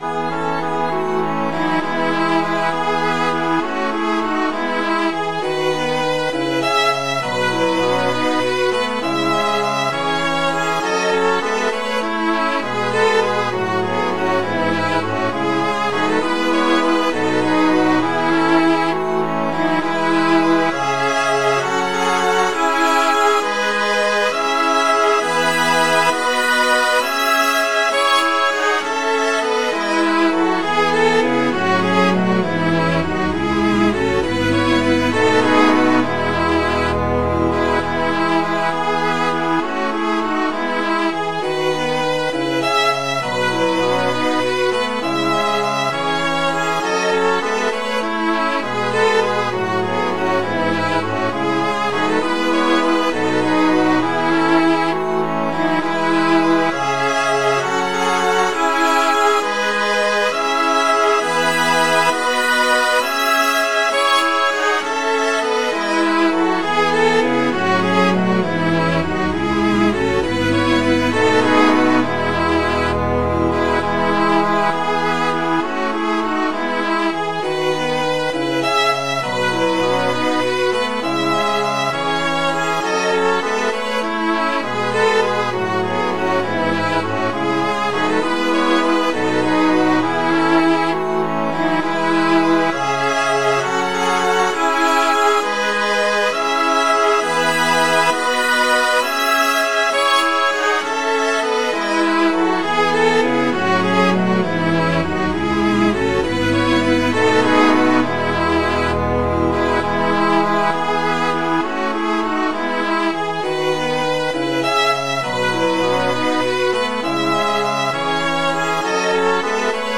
lovel.mid.ogg